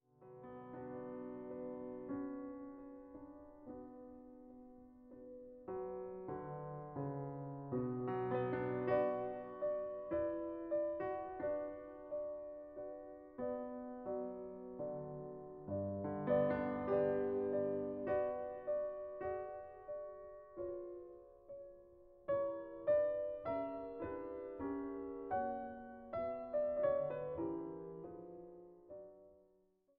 Nr. 5 in D-Dur Molto allegro